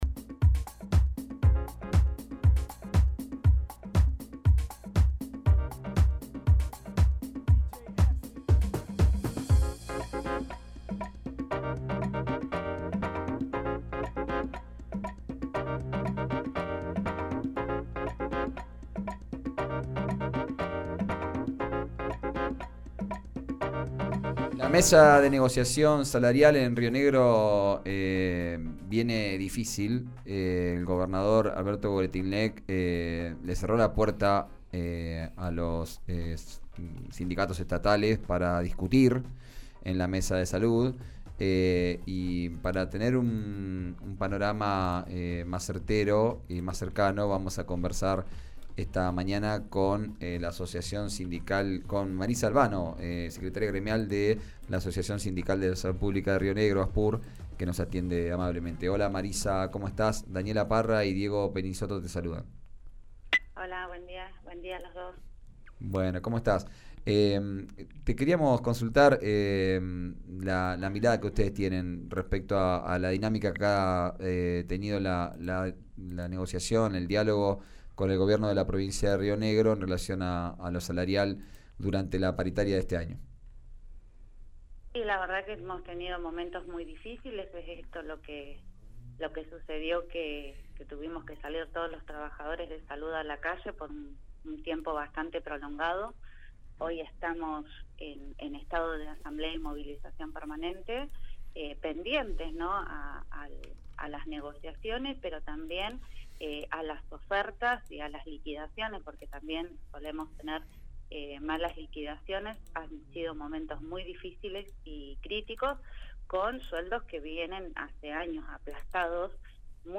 Hoy se retomará la mesa de salarial de Salud con el Gobierno Provincial. Desde el gremio Asspur dialogaron con RÍO NEGRO RADIO previo a este encuentro.